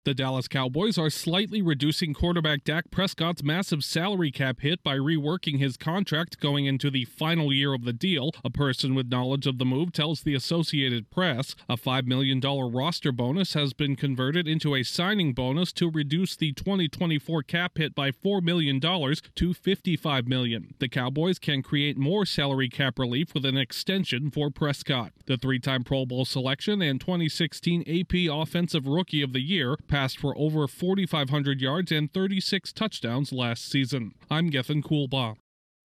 The Cowboys are re-working star quarterback Dak Prescott’s contract in a move that could precede a possible extension. Correspondent